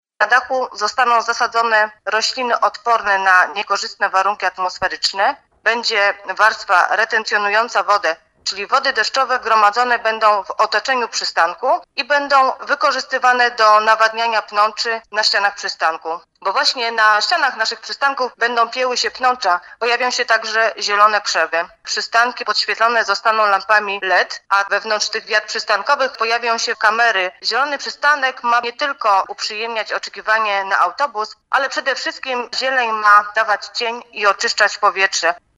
Mówi wiceprezydent Stalowej Woli Renata Knap